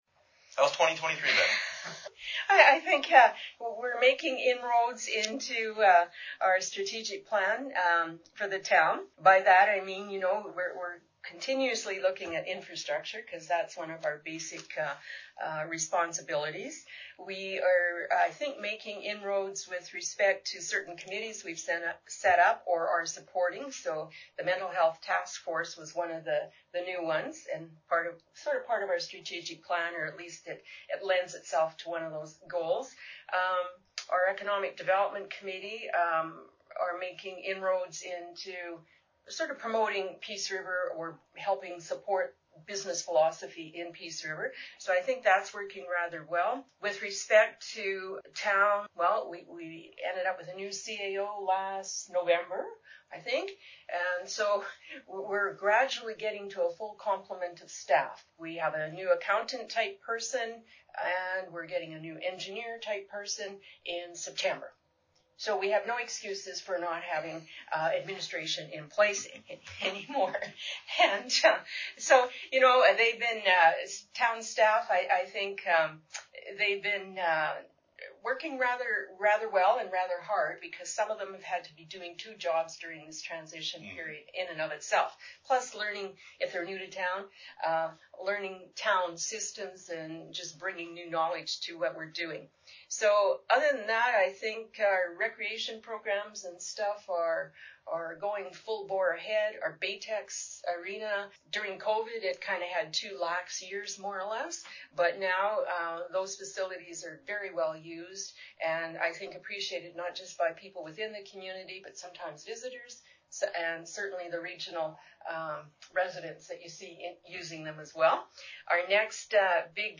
Conversation with Peace River Mayor Manzer
PR_Mayor_SitDown.mp3